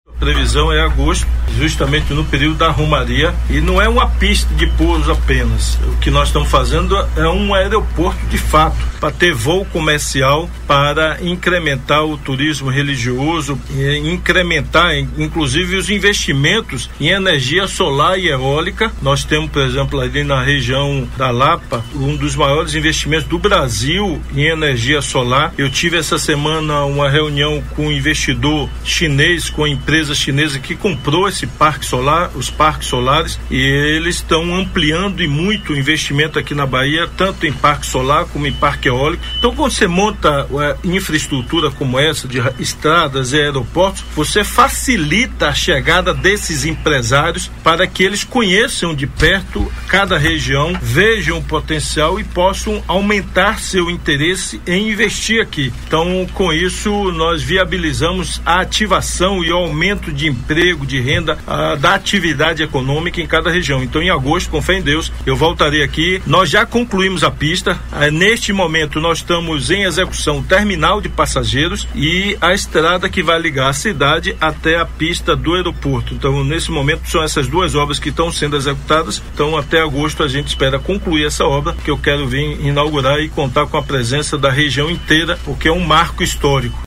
Ouça o áudio de Rui Costa, Governador do Estado da Bahia
Audio-Governador-Rui-Costa.mp3